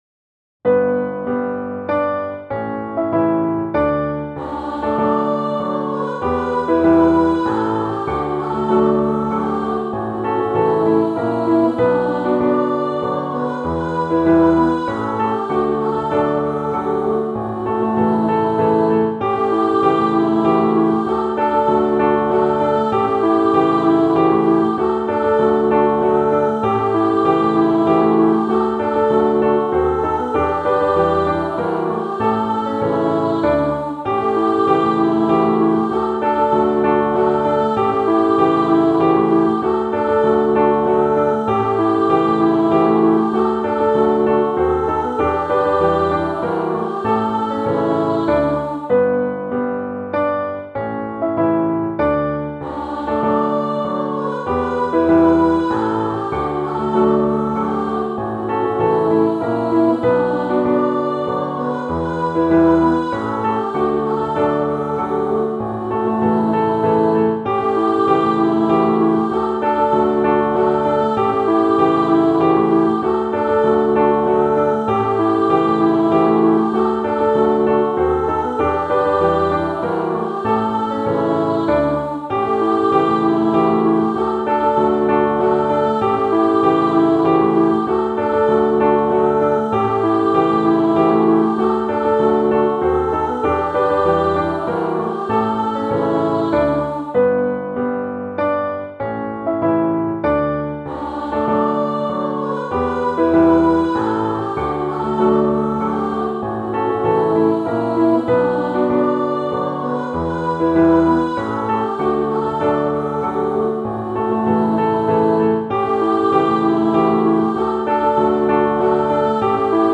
Podkład muzyczny